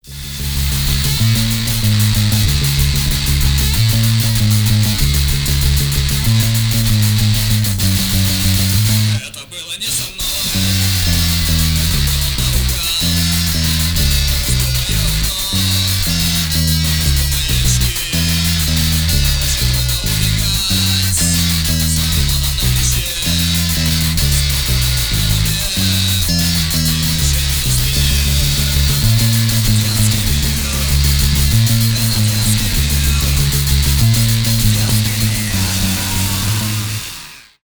рок , панк-рок